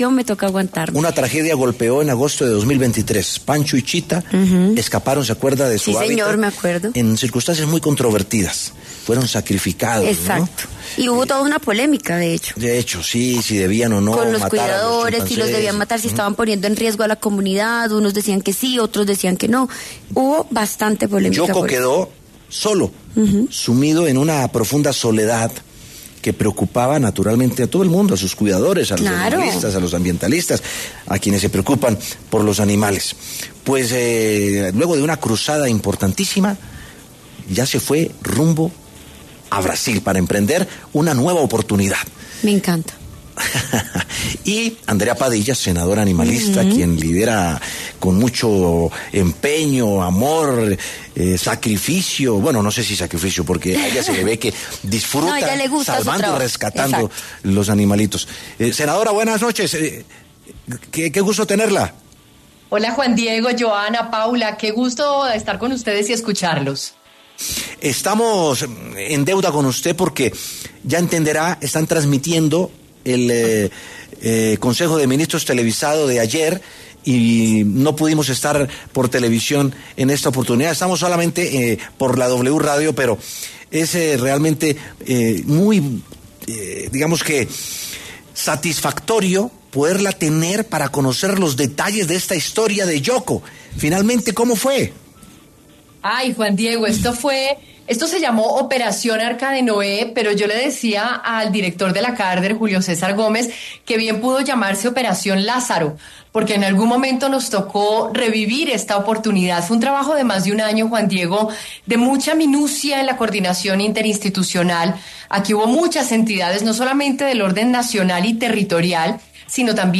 Andrea Padilla, senadora animalista, pasó pro W Sin Carreta y habló sobre la historia de ‘Yoko’, el último chimpancé que permanecía bajo cuidado humano, que fue transportado hasta Brasil, donde tendrá un nuevo hogar.